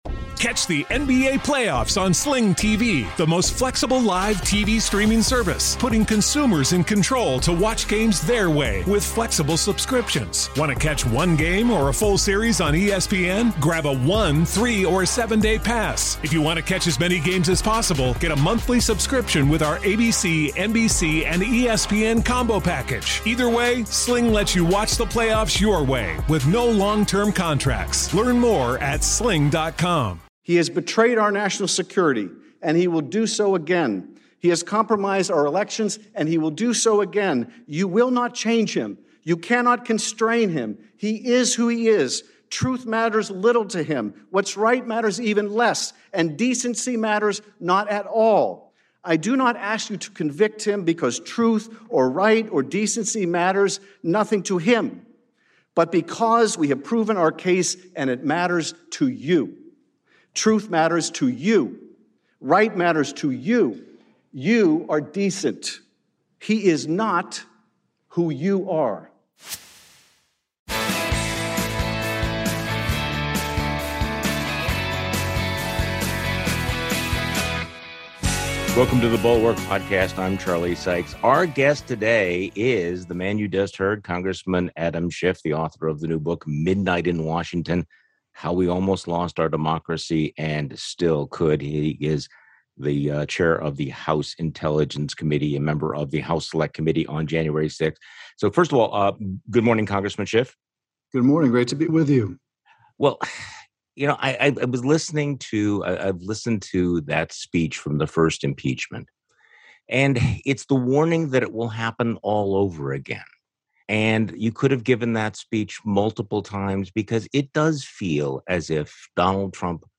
Rep. Adam Schiff joins Charlie Sykes on today's podcast.
Special Guest: Adam Schiff.